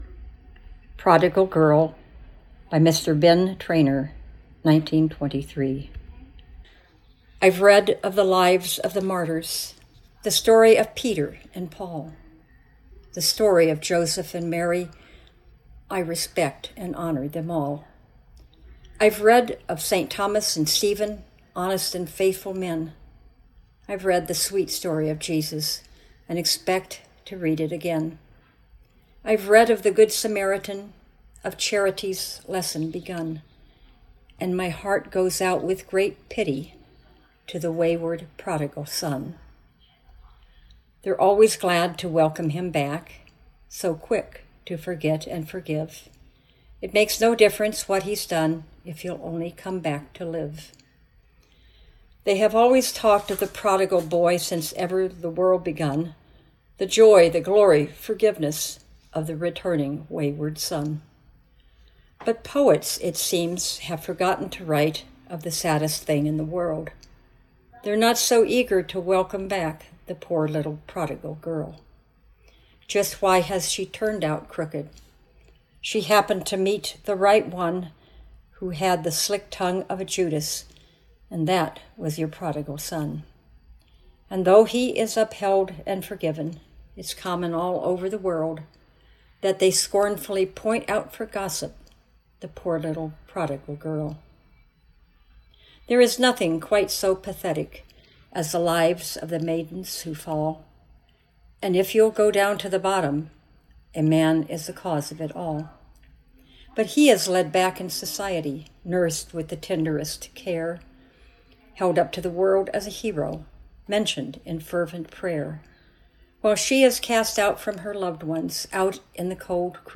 Narrative reading of Prodigal Girl Poem